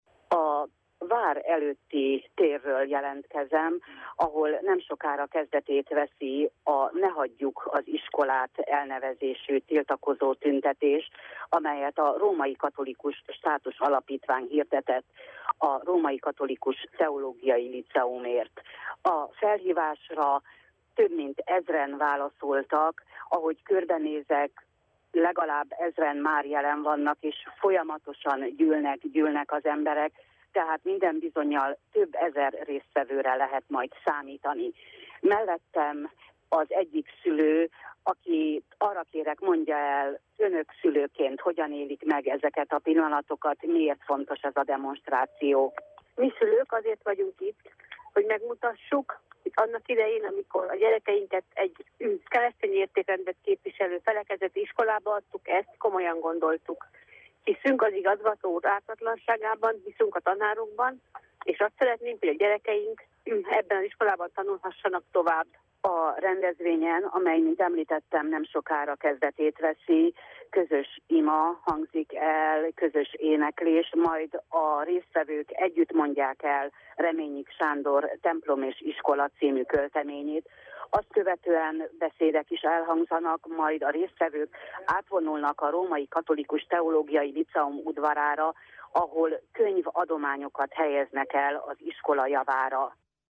Marosvásárhelyen, a Vársétányon, Kolozsváron és Sepsiszentgyörgyön, a Főtéren, Nagyváradon az Ady múzeumnál, délután 3 órakor kezdődtek a szolidaritási akciók.